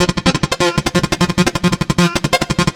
Key-rythm_arp_131.1.1.wav